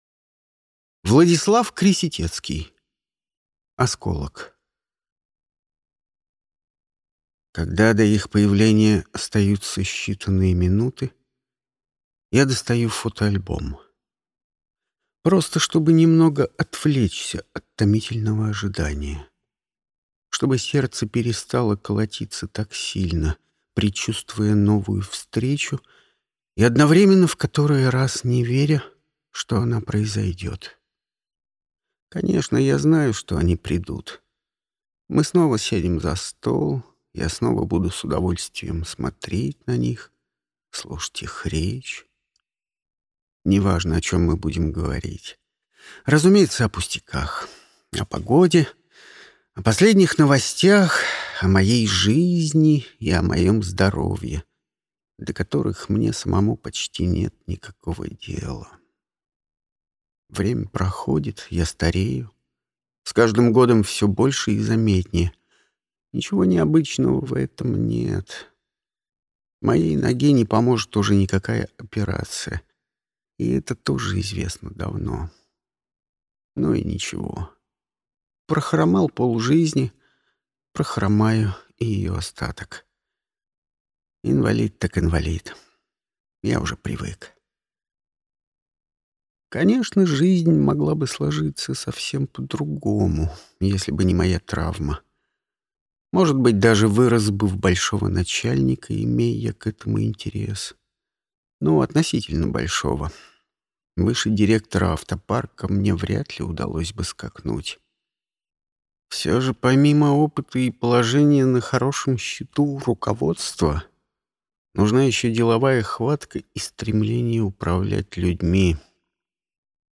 Аудиокнига Осколок | Библиотека аудиокниг
Прослушать и бесплатно скачать фрагмент аудиокниги